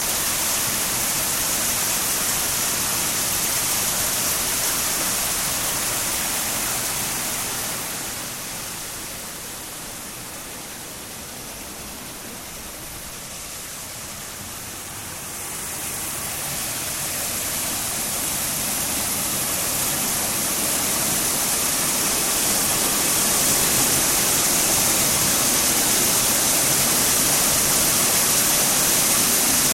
Peas being delivered for storage
Peas from Colchester being transferred for storage at Sutton St James